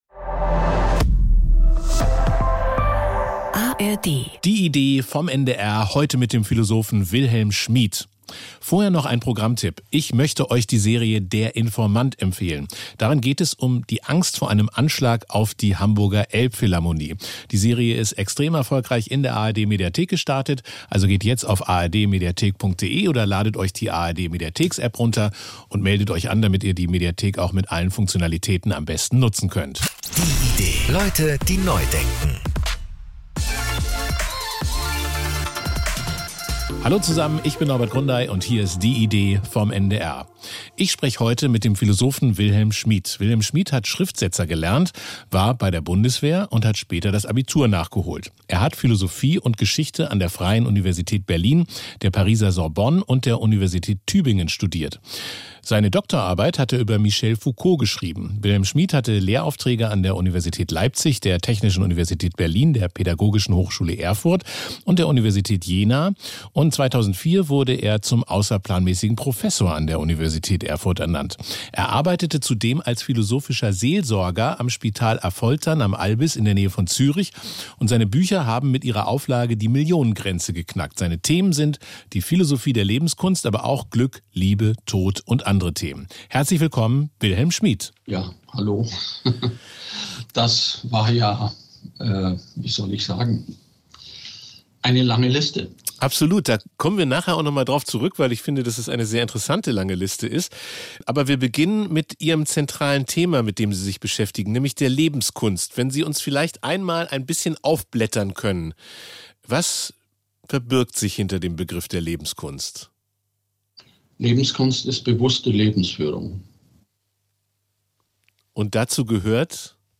mit dem Philosophen Wilhelm Schmid